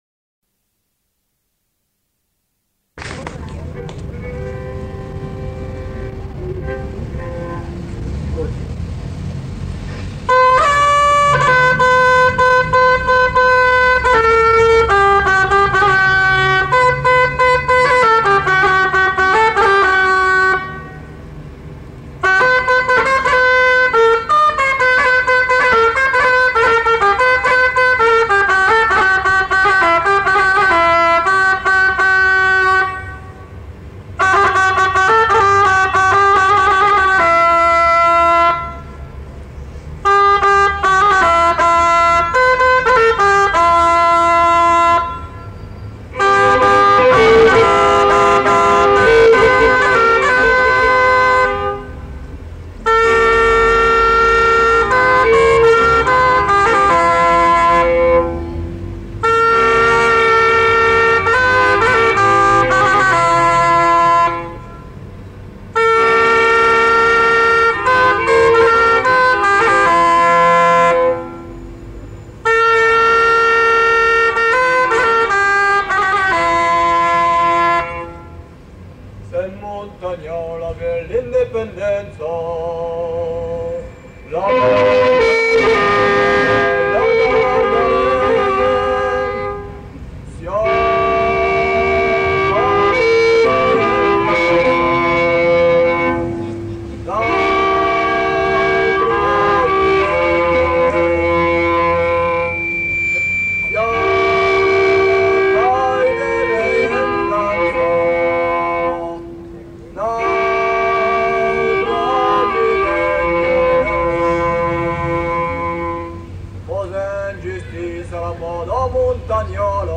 Lieu : Pinerolo
Genre : chanson-musique
Type de voix : voix d'homme
Production du son : chanté
Instrument de musique : graile ; violon